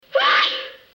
woman sneeze 3